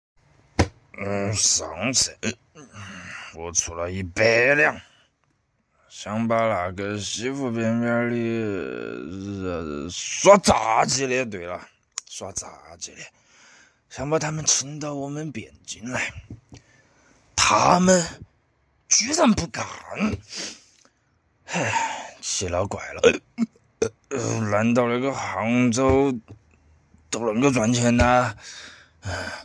为期两周的海选阶段，我们收到了几千位玩家50000+个方言配音作品。
1、重庆市 渝中区 重庆方言
他们不仅会讲方言，还会细心地设计不同的生活场景、人物身份、以及恰当且饱满的情绪，一出口就能让大家身临其境。